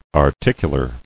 [ar·tic·u·lar]